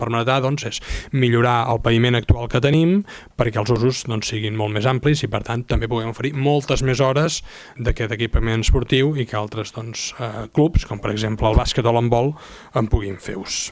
L’alcalde Marc Buch exposava que l’actuació permetrà que la instal·lació pugui acollir activitat d’entitats usuàries del pavelló del Parc Dalmau: